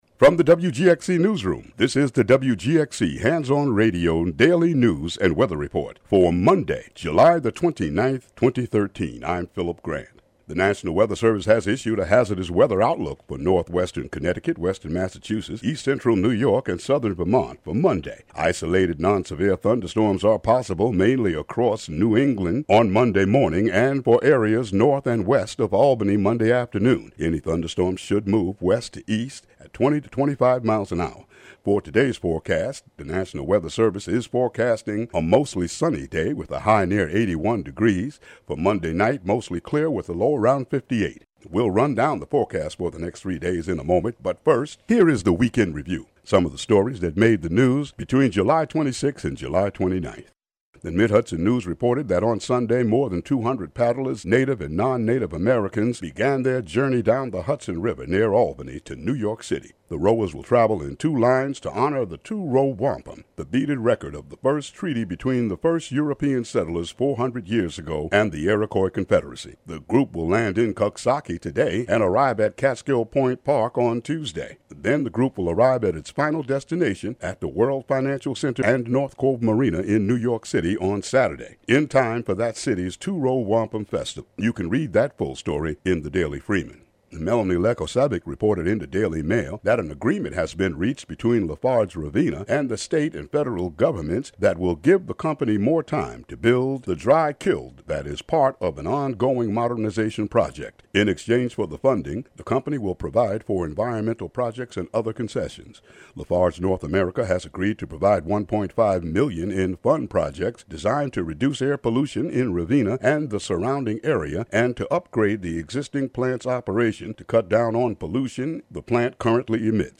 Local news and weather for Monday, July 29, 2013.